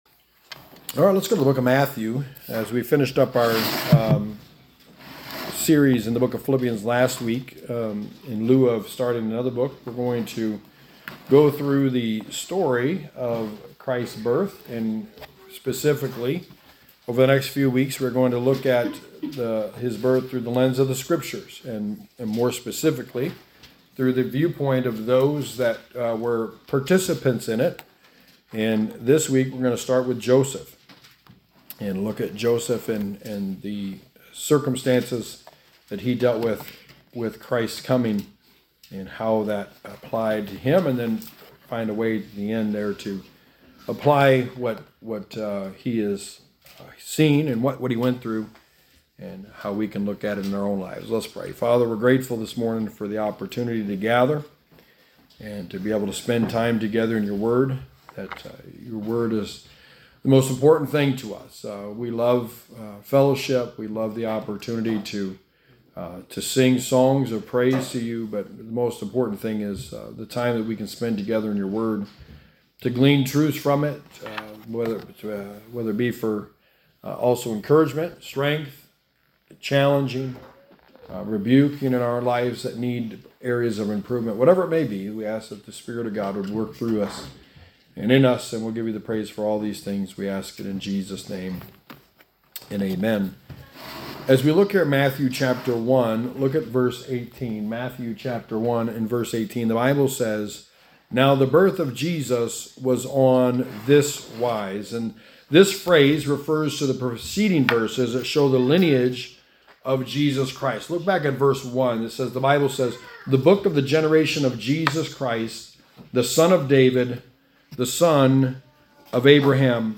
Sermon 1: The Birth of Christ – Joseph
Service Type: Sunday Morning